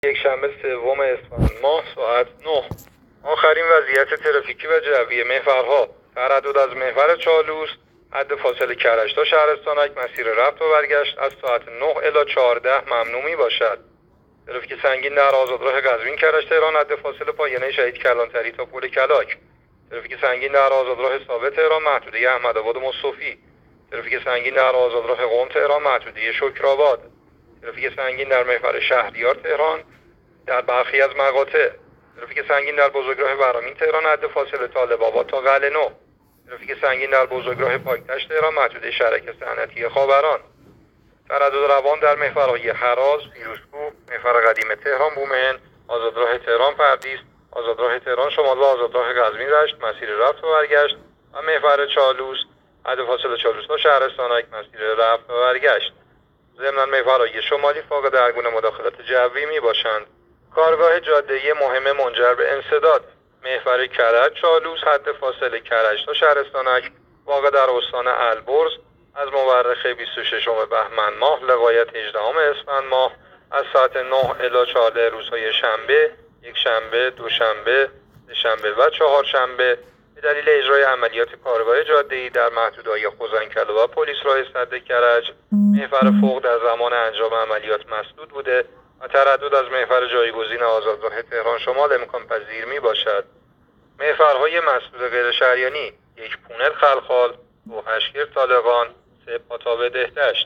گزارش رادیو اینترنتی از آخرین وضعیت ترافیکی جاده‌ها ساعت ۹ سوم اسفند؛